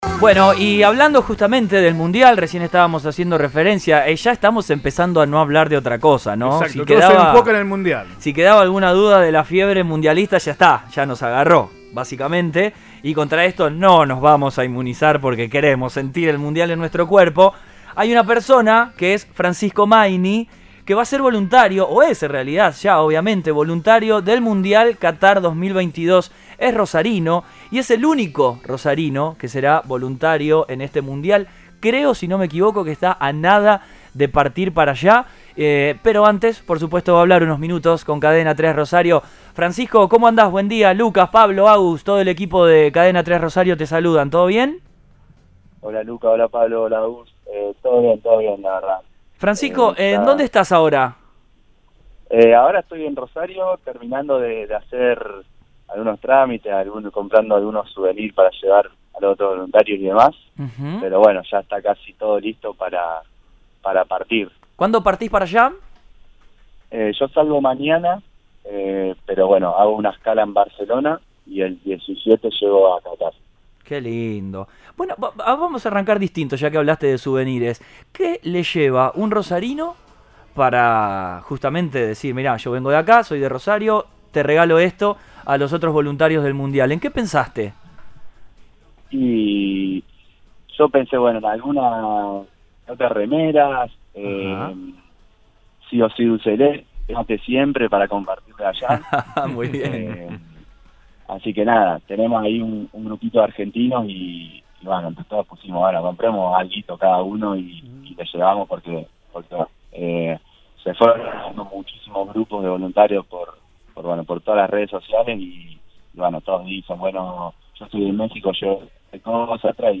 A punto de partir rumbo a su aventura mundialista, el joven habló con Cadena 3 Rosario y contó de qué se encargará en la tierra árabe, además de los detalles de su acceso a ese lugar anhelado.